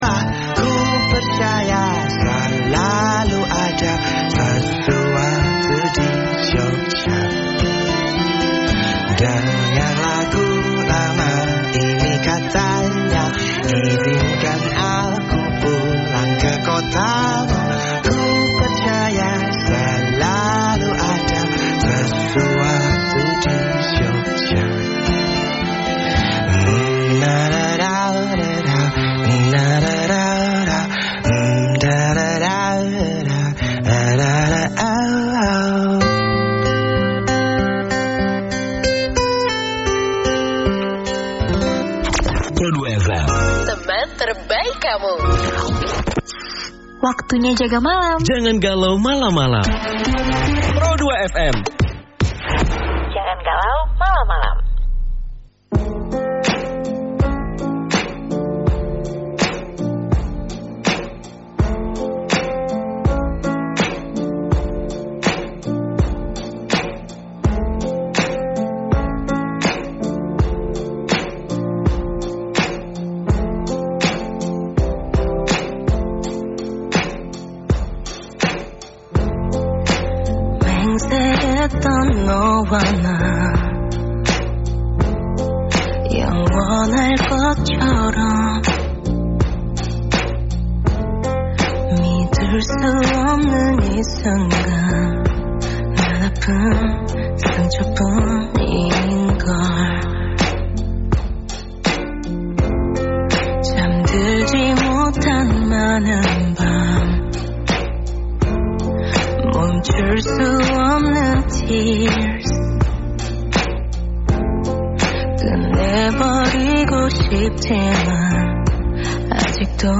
Rekaman Siaran Pro 2 RRI Yogyakarta FM 102.5 Mhz